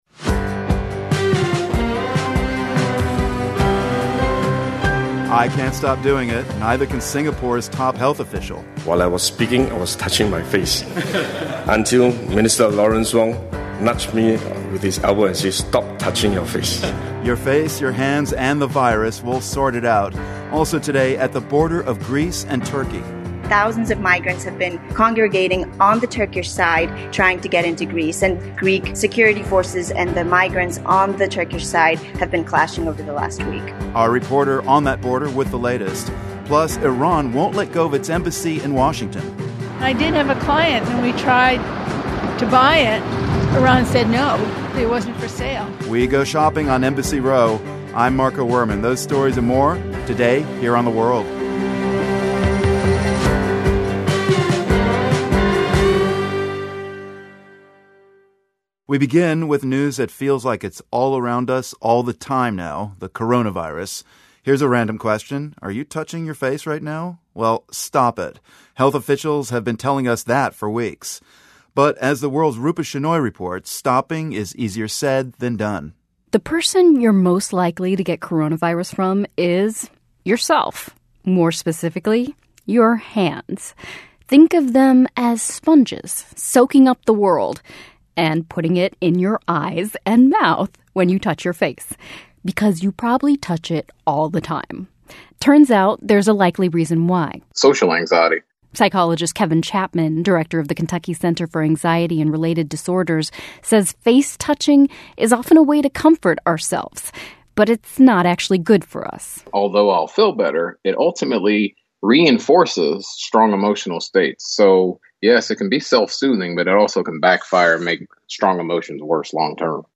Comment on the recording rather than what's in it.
at the border in Orestiada, Greece. And, how we use our hands to interact with others is different across cultures, and habits are changing because of the novel coronavirus. Also, a social historian commemorates the lives of the women murdered by London's 19th serial killer Jack the Ripper.